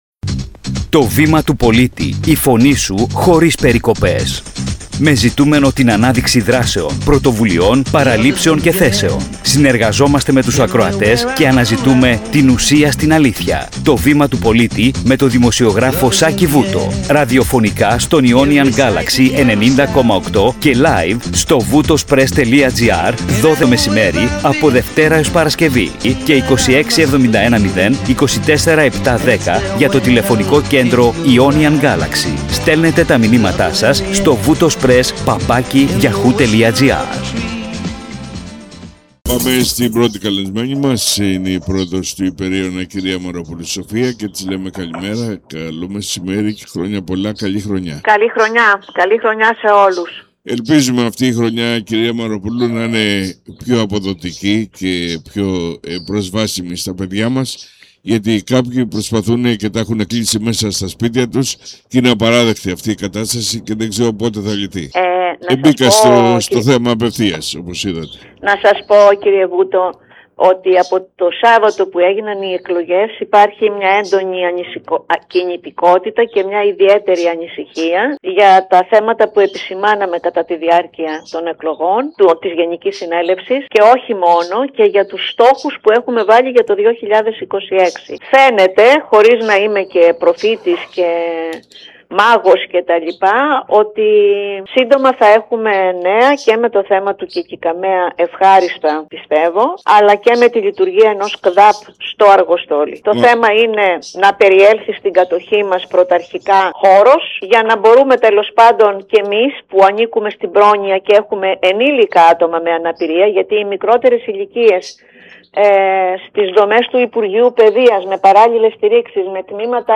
Το Βήμα του Πολίτη – Συνέντευξη